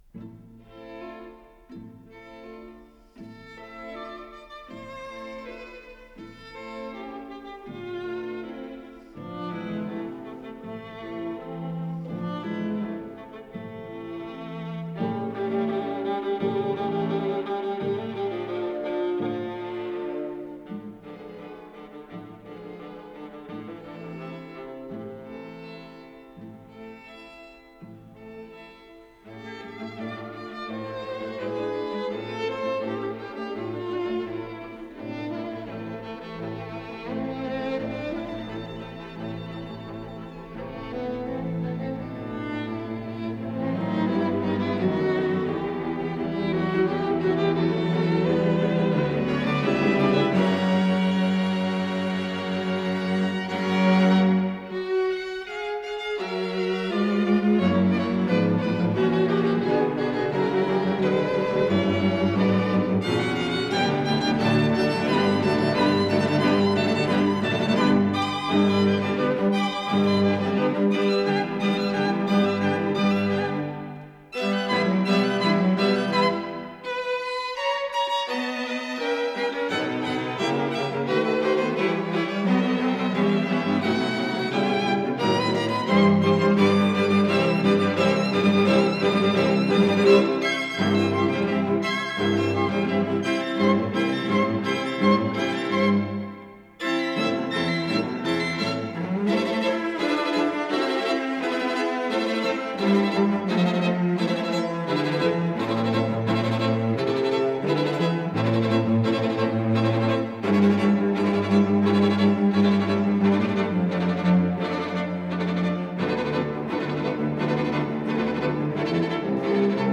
Исполнитель: Струнный секстет
Название передачи Воспоминание о флоренции Подзаголовок Секстет для 2-х скрипок, 2-х альтов, 2-х виолончелей.
Ре минор Код ДКС-23773 Фонд Без фонда (ГДРЗ) Редакция Музыкальная Общее звучание 00:35:46 Дата добавления 13.10.2024 Прослушать